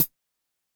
Perc (1).wav